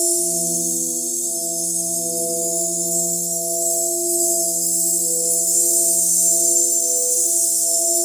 shimmer_sparkle_loop_02.wav